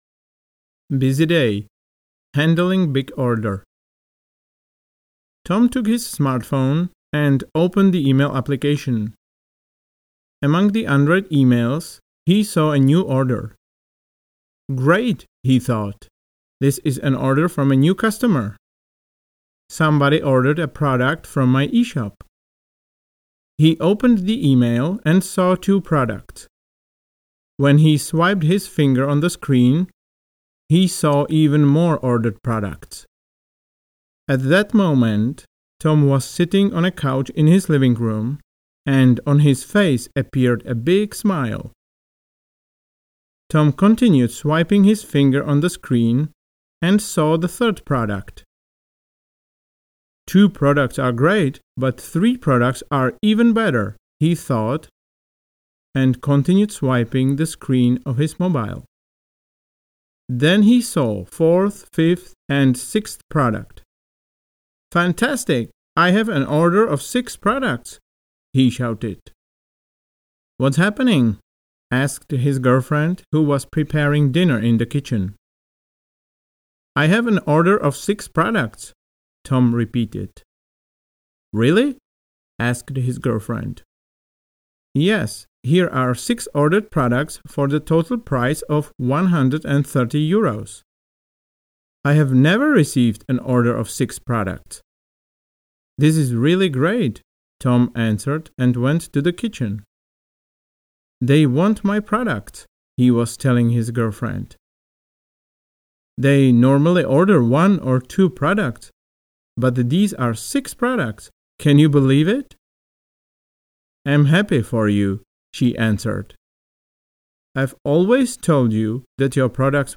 Audio knihaBusy Day - Handling Big Order
Ukázka z knihy
Rychlejší část s pauzami má 45 minut.